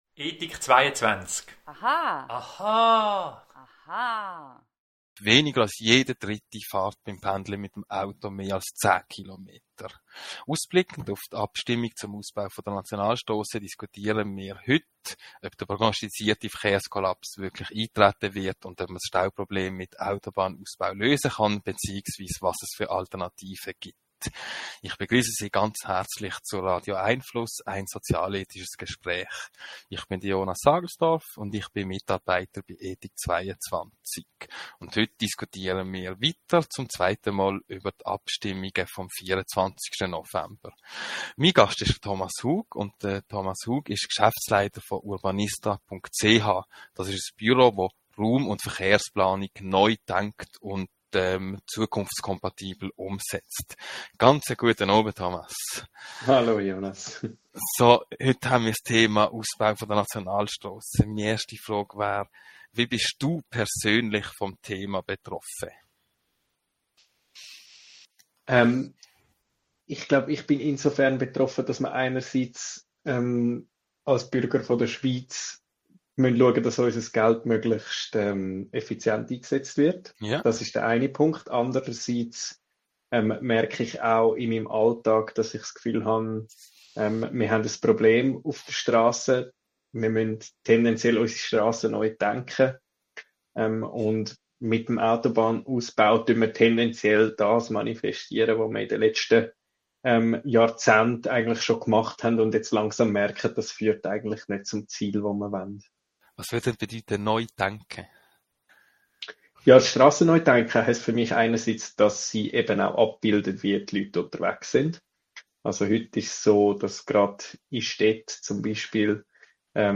Weitere interessante Gedanken zum Thema hören Sie im Podcast unseres Gesprächs vom 6. November 2024 mit